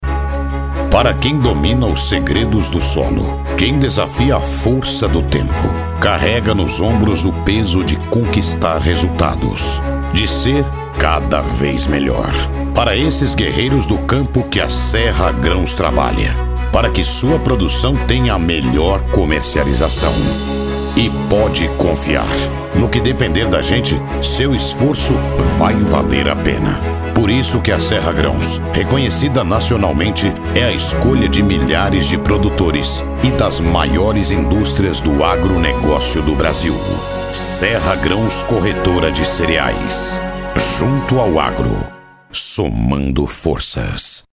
Spot-45-Serra-Graos-Pra-que-domina_01.mp3